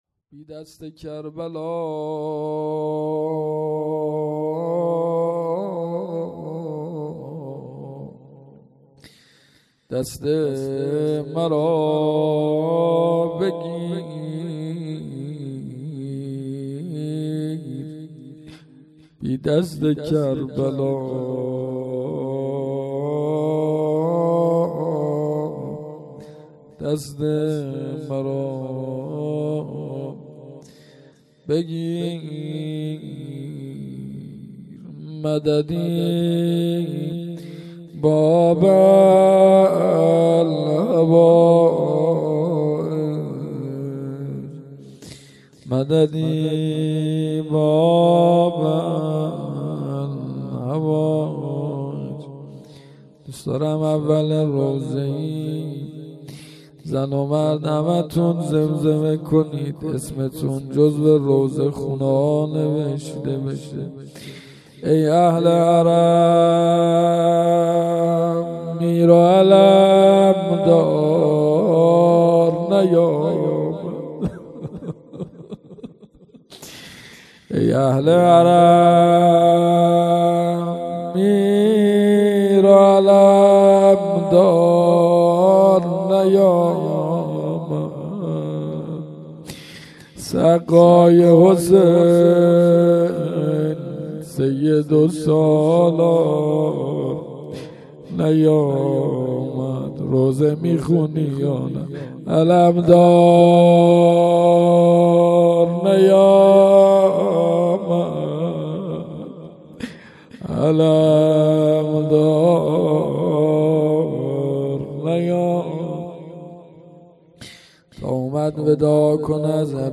مراسم شهادت امام جواد علیه السلام ۱۴۰۴